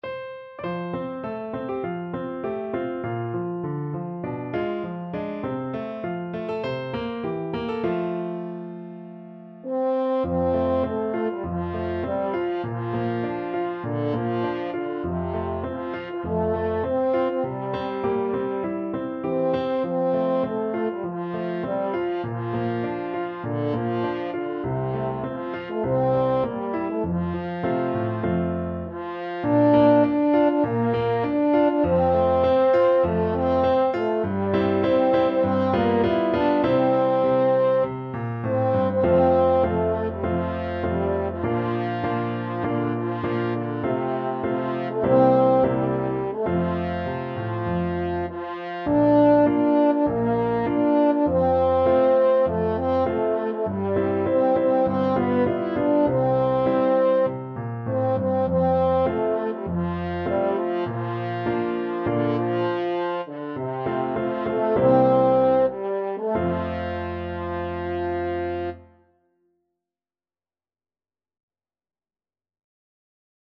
French Horn
F major (Sounding Pitch) C major (French Horn in F) (View more F major Music for French Horn )
~ = 100 Moderato
4/4 (View more 4/4 Music)
C4-E5
Traditional (View more Traditional French Horn Music)